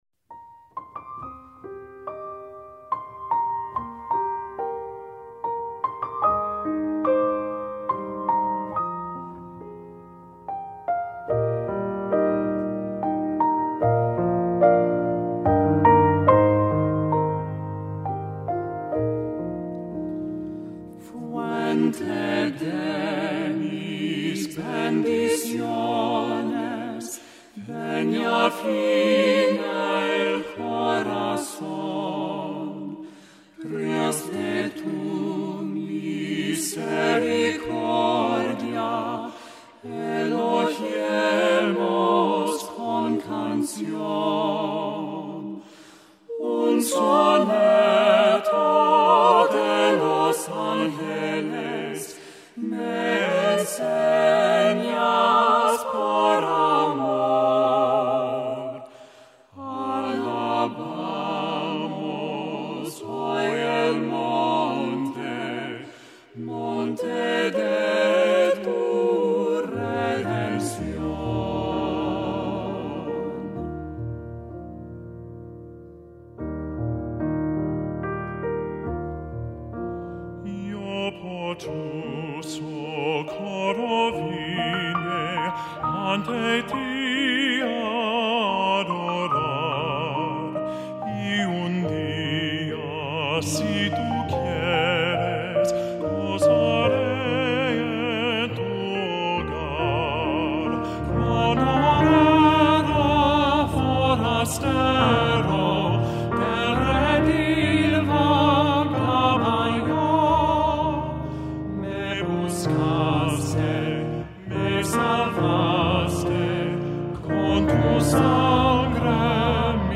SATB Choir and Piano
Hymn arrangement